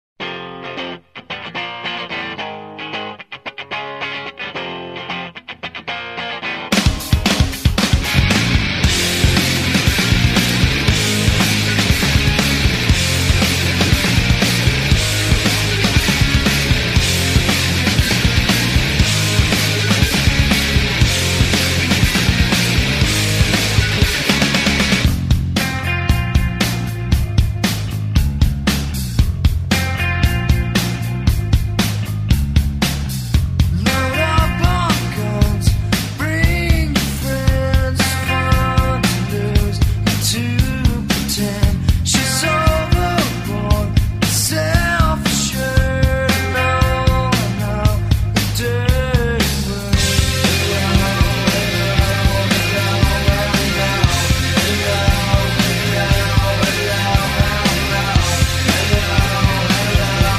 Рок
был записан на хорошей студии и за хорошие деньги